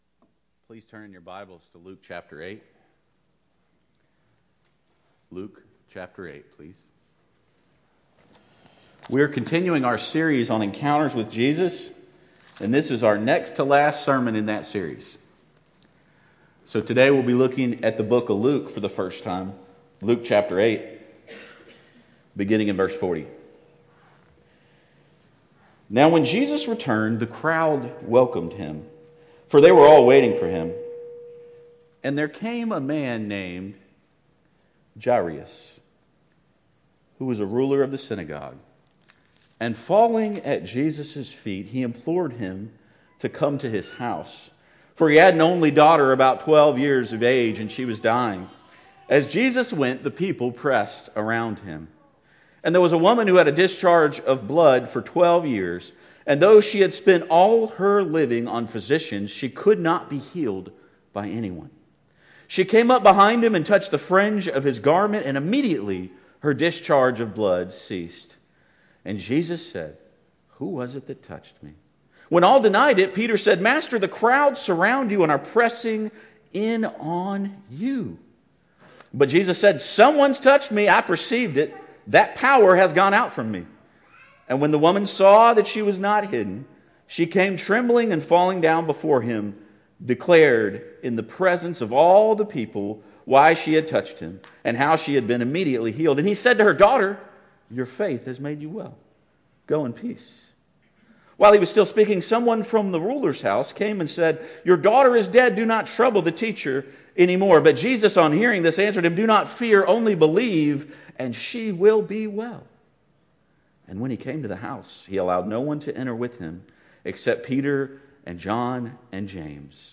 Encounters with Jesus Sunday Morning